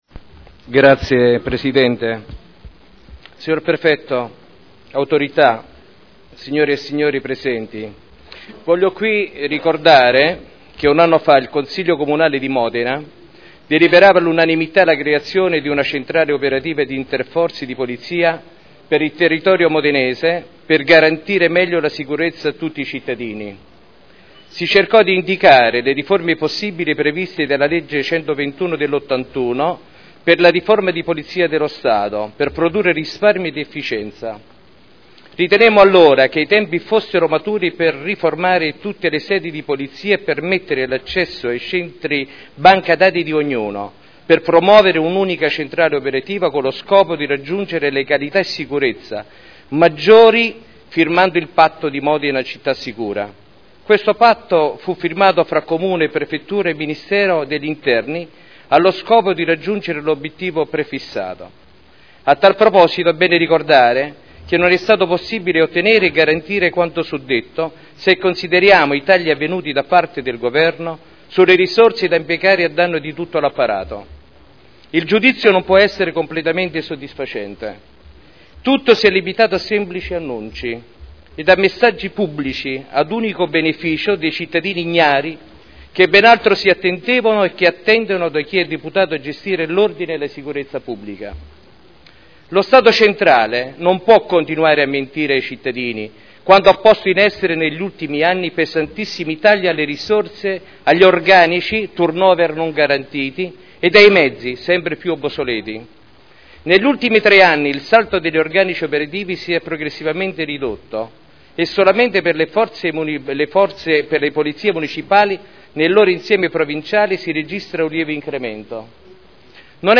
Seduta del 17/05/2012 "Patto per Modena sicura". Dibattito